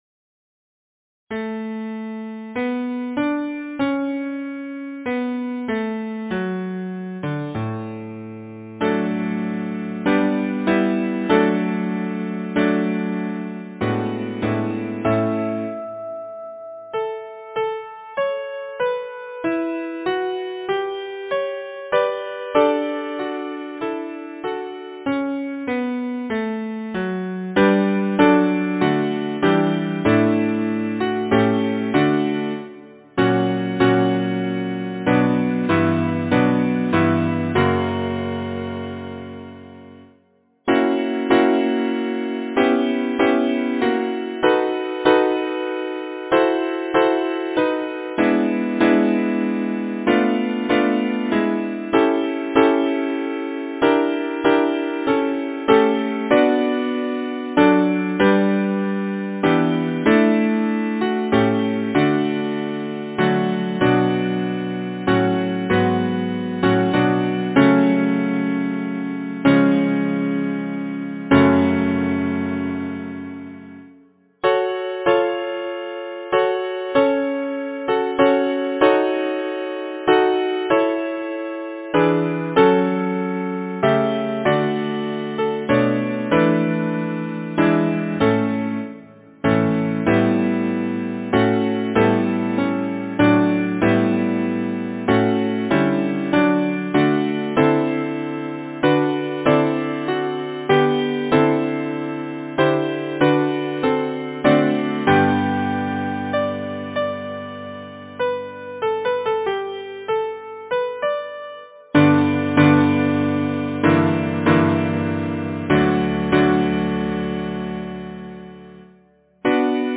Title: First shall the heavens want starry light Composer: Herbert Walter Wareing Lyricist: Thomas Lodge Number of voices: 4vv Voicing: SATB, divisi Genre: Secular, Partsong
Language: English Instruments: A cappella